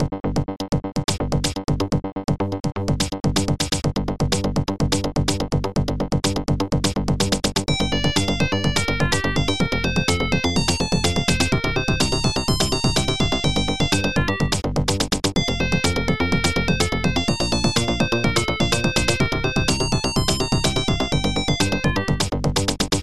Protracker Module